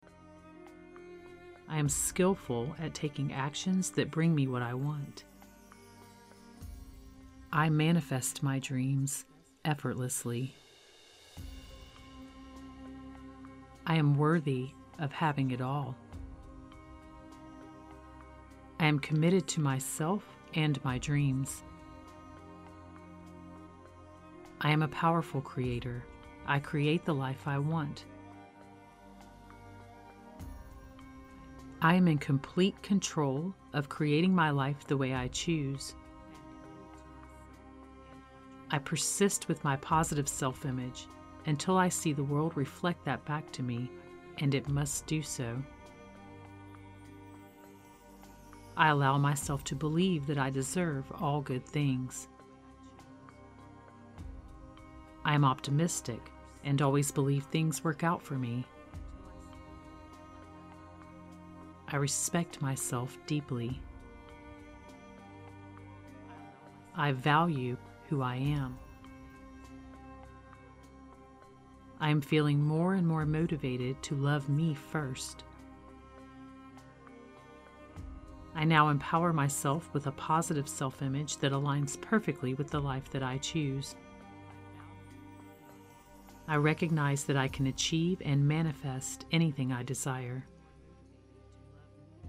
The musical track has been tuned to 528Hz, known as the miracle tone and the affirmations are delivered with dual induction technology (the sound moves from ear-to-ear and back to center to further impress the subconscious mind.)
Positive-Self-Image-Affirmations-Sample.mp3